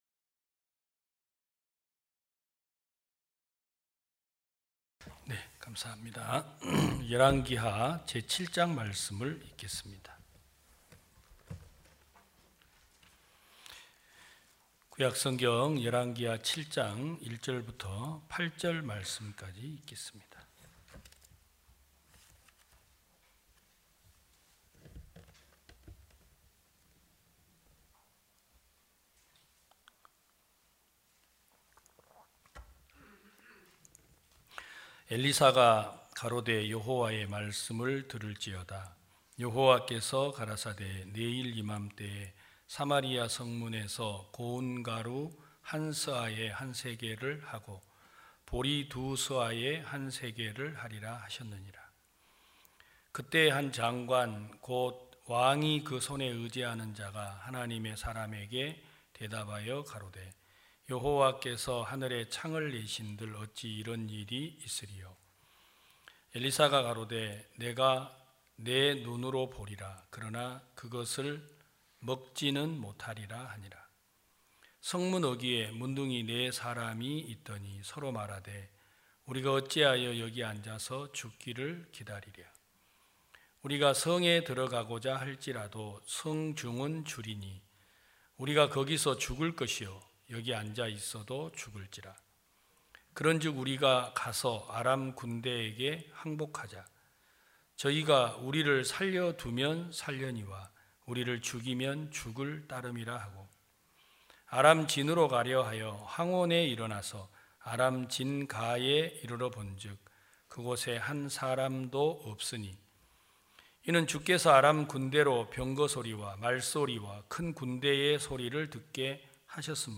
2021년 10월 24일 기쁜소식부산대연교회 주일오전예배
성도들이 모두 교회에 모여 말씀을 듣는 주일 예배의 설교는, 한 주간 우리 마음을 채웠던 생각을 내려두고 하나님의 말씀으로 가득 채우는 시간입니다.